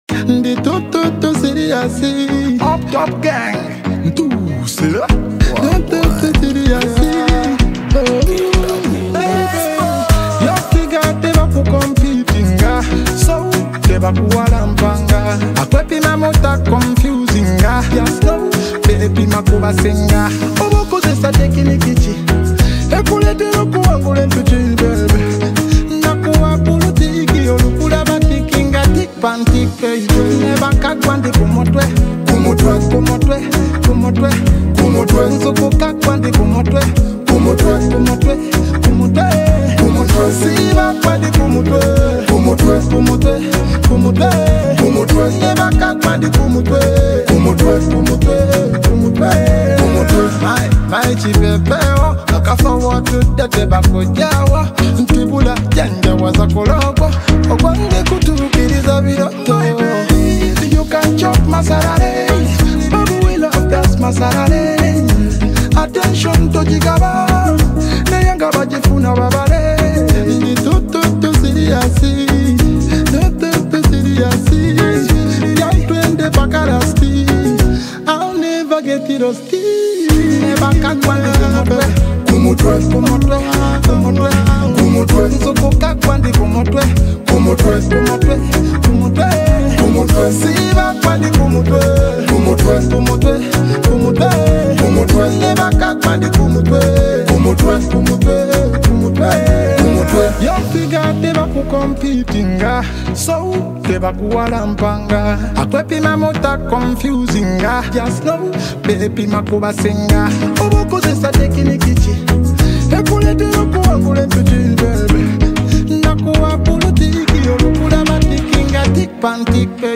The beat is gritty, with that raw Kampala bounce